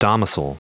Prononciation du mot domicil en anglais (fichier audio)
Prononciation du mot : domicil